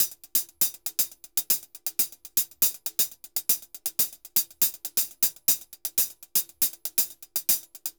HH_Candombe 120_1.wav